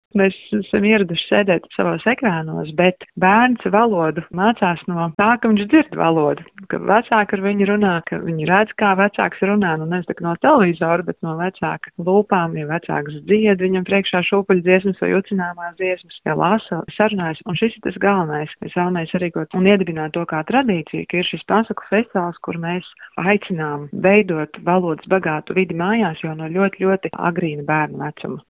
intervijā